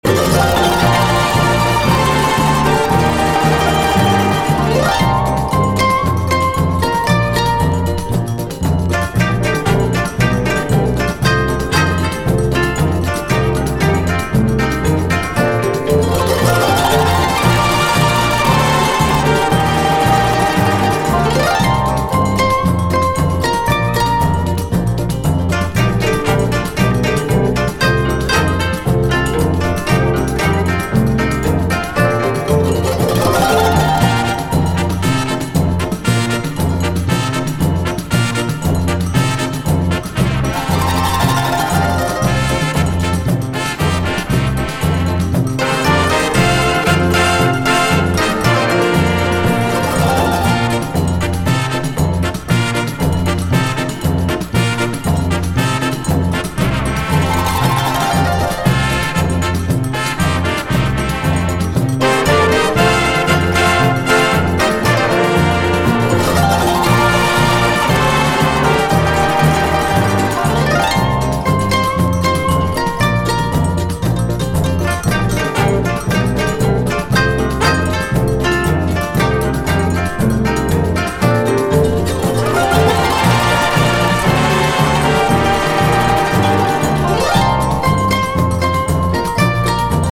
DISCO / DANCE CLASSIC / JAPANESE DISCO / CITY POP
アーバン・メロウなこみ上げレディ・ソウル！